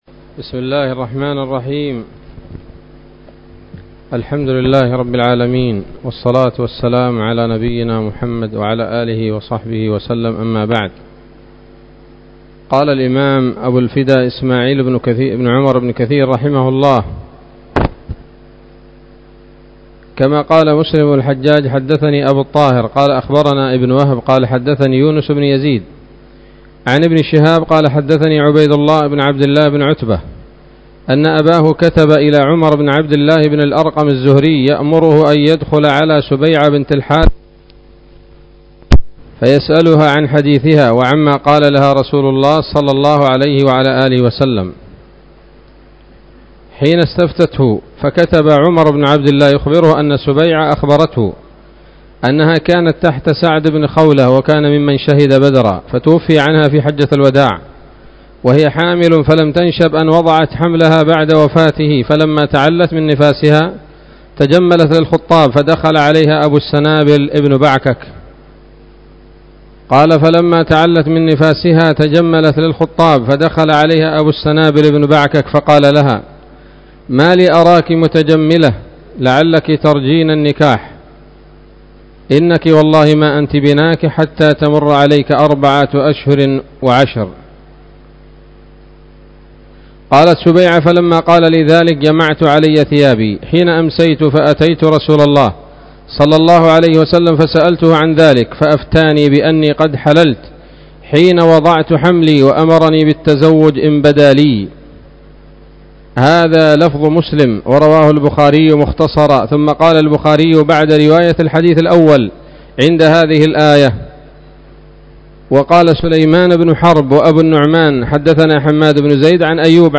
065 سورة الطلاق الدروس العلمية تفسير ابن كثير دروس التفسير
الدرس الخامس من سورة الطلاق من تفسير ابن كثير رحمه الله تعالى